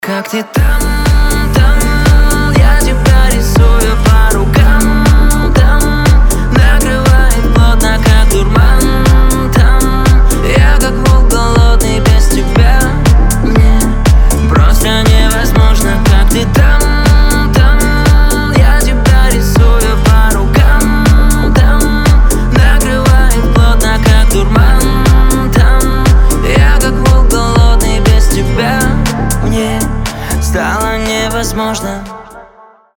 поп
красивый мужской голос